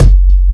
Kik Fatrok 03.wav